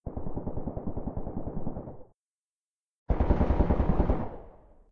Commotion1.ogg